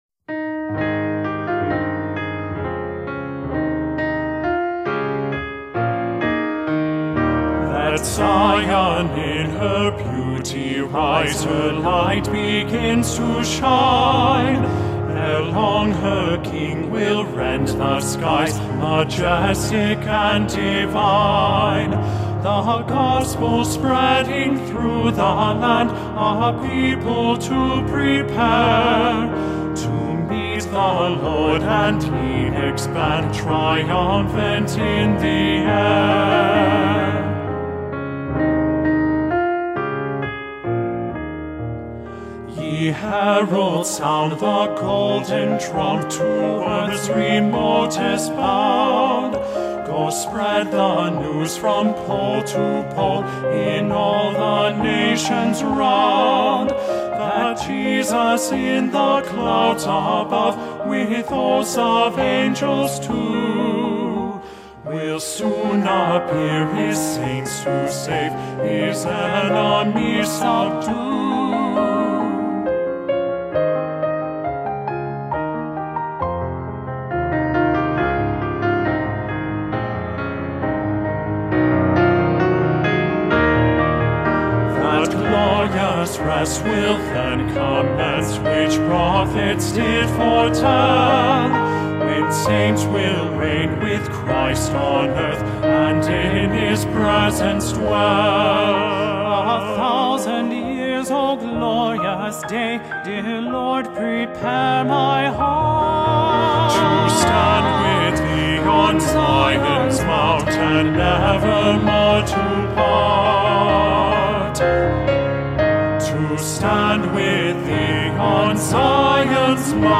Tenor/Bass Duet and Piano
Hymn arrangement